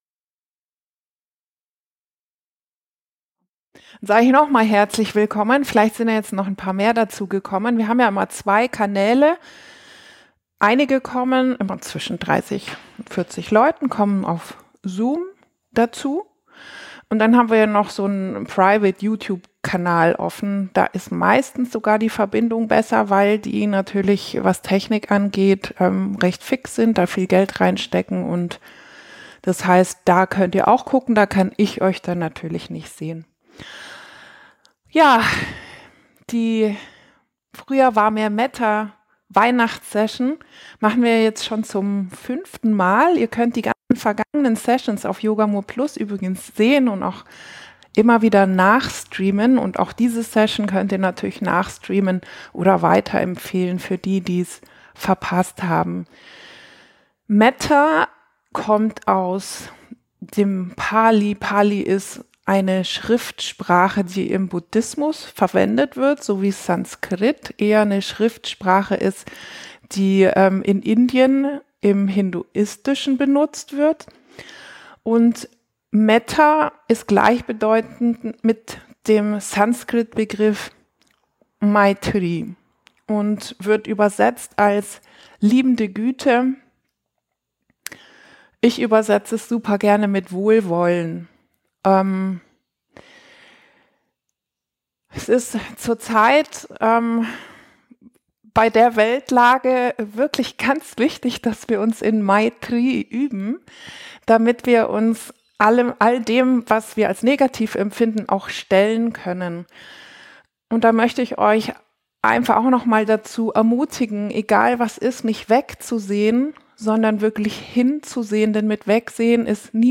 Aufzeichnung der Live-Session vom 22.12.2024 🎄